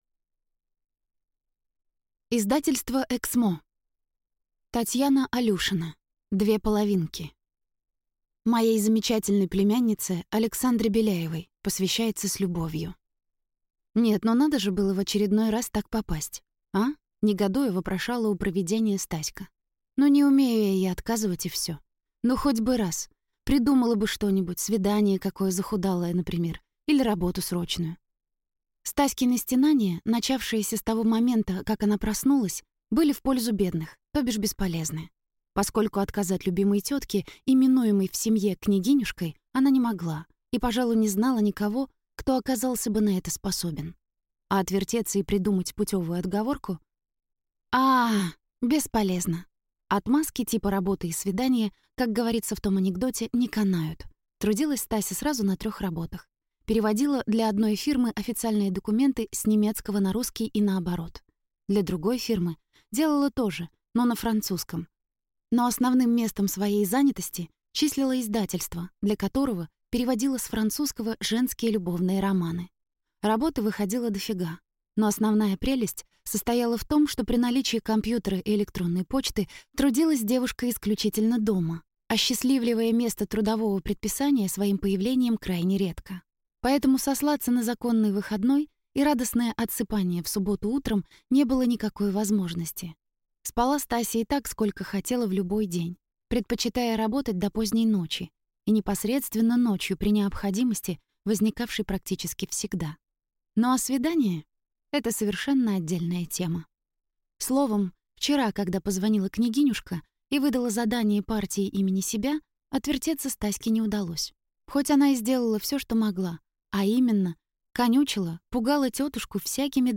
Аудиокнига Две половинки | Библиотека аудиокниг